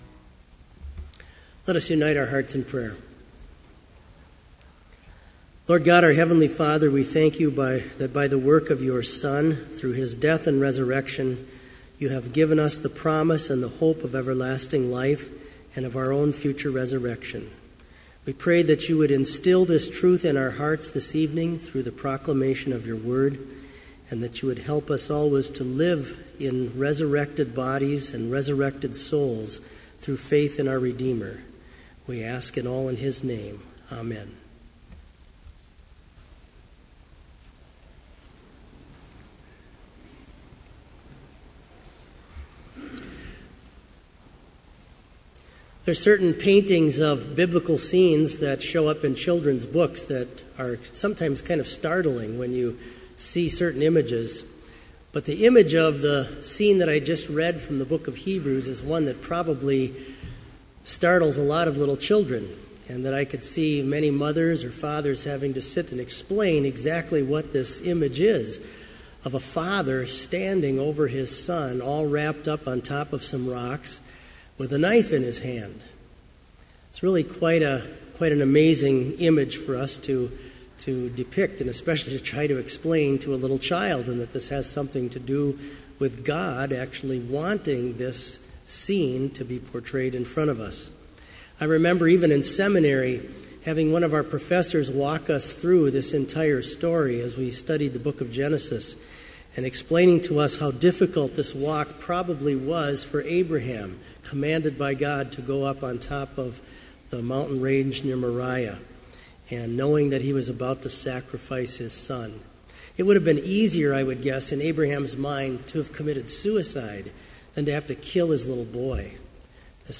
• Prelude
• Versicles (led by the choir)
• Homily
This Vespers Service was held in Trinity Chapel at Bethany Lutheran College on Wednesday, April 8, 2015, at 5:30 p.m. Page and hymn numbers are from the Evangelical Lutheran Hymnary.